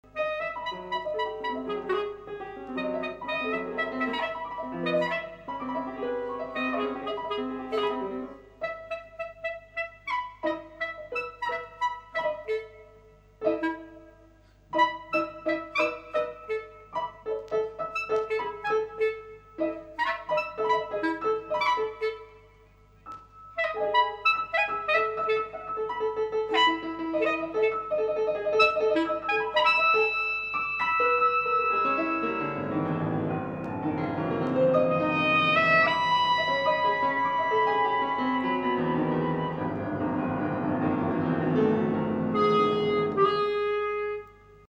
audio 44kz stereo